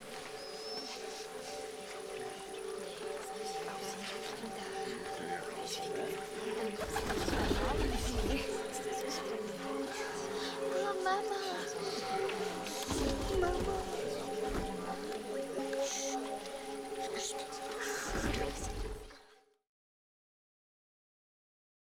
Emergence of human voices